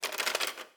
Babushka / audio / sfx / Kitchen / SFX_Cutlery_05.wav
SFX_Cutlery_05.wav